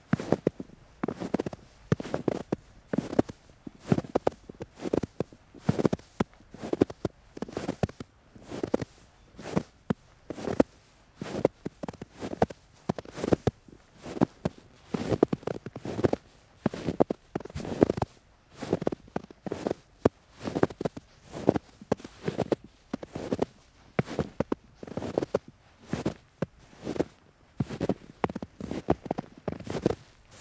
Steps in the snow #3 — Free Sound Effect Download | BigSoundBank - BigSoundBank
Several quick steps in the snow.